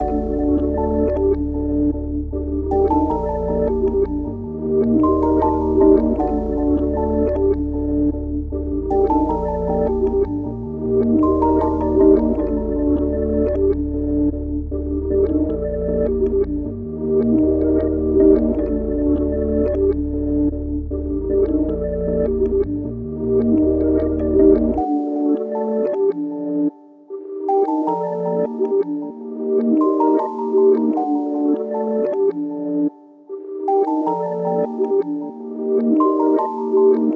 Thundercat_155_Bpm_Labcook.wav